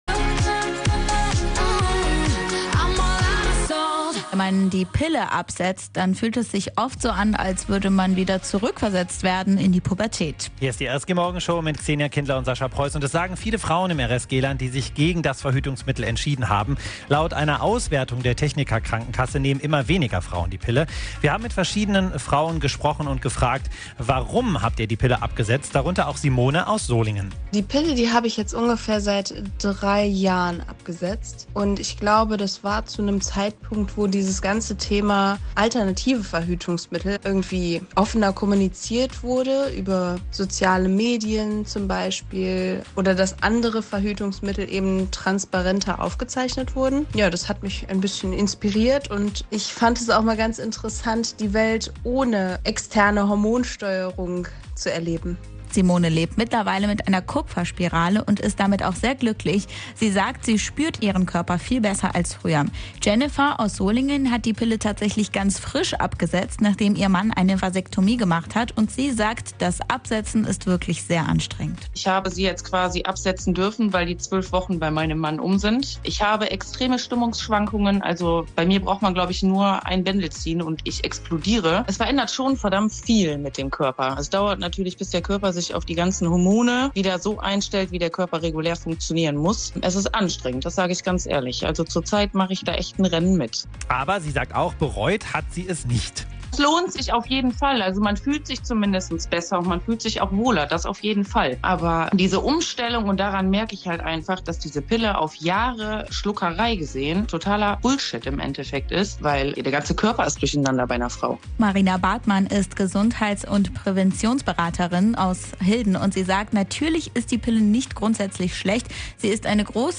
Wir haben mit Frauen aus dem RSG-Land über die Pille und alternative Verhütungsmethoden gesprochen.